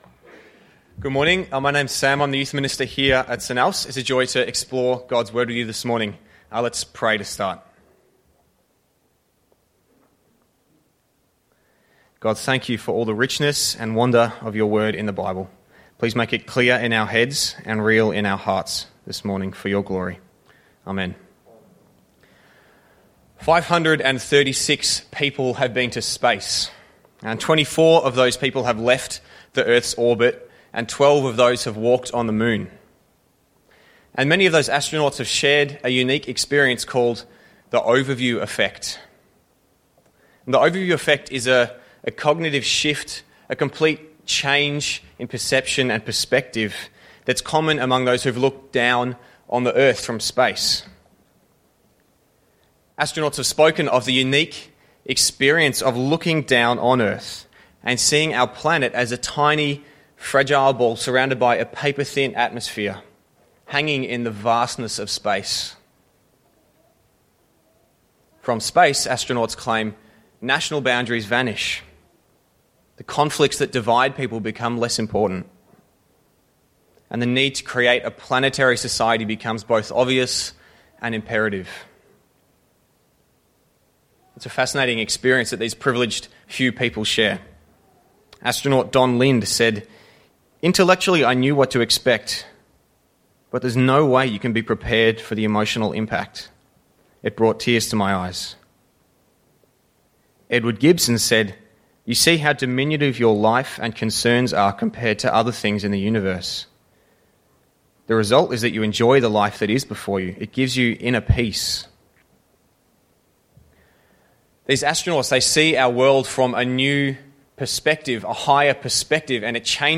At morning and evening services we are looking at the figure of Samson. He is without doubt a flawed leader and yet used in surprising ways by God.